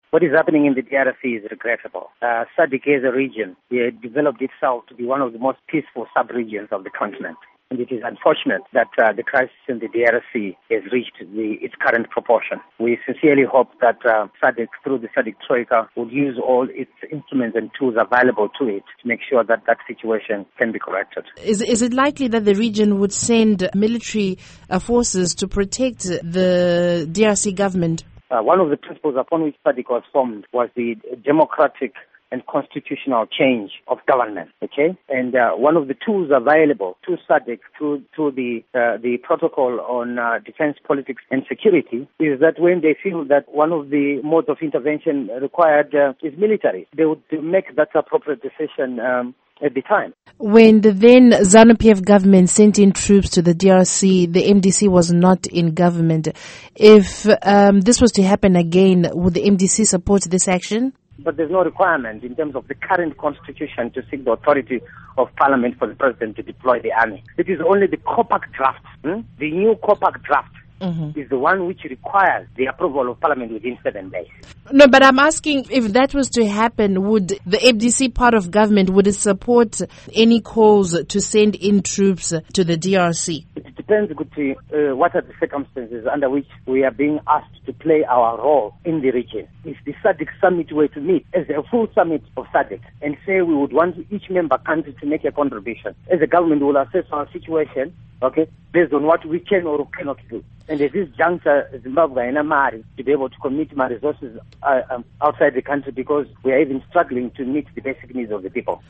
Interview with Jameson Timba